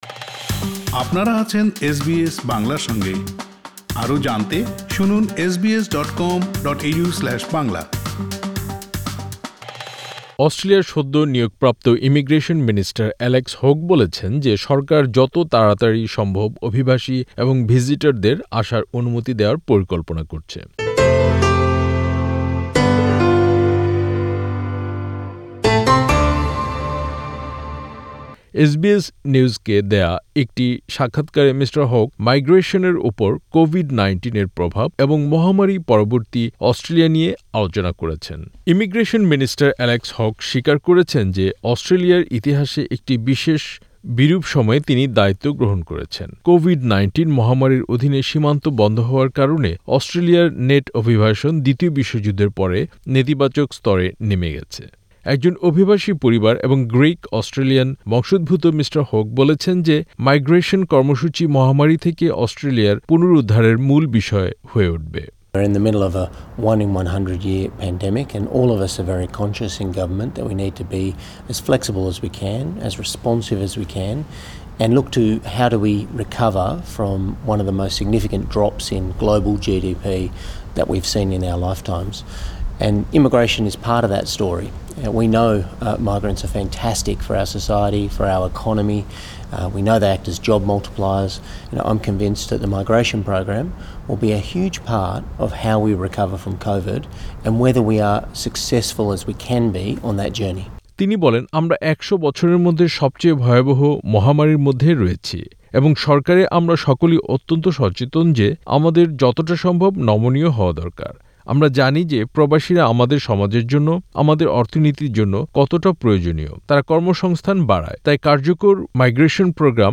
অস্ট্রেলিয়ার সদ্য নিয়োগপ্রাপ্ত ইমিগ্রেশন মিনিস্টার অ্যালেক্স হোক বলেছেন যে সরকার যত তাড়াতাড়ি সম্ভব অভিবাসী এবং ভিজিটরদের আসার অনুমতি দেওয়ার পরিকল্পনা করছে। এসবিএস নিউজকে দেওয়া একটি সাক্ষাৎকারে মিঃ হোক মাইগ্রেশনের উপর কোভিড ১৯-এর প্রভাব এবং একটি মহামারী-পরবর্তী অস্ট্রেলিয়া নিয়ে আলোচনা করেছেন।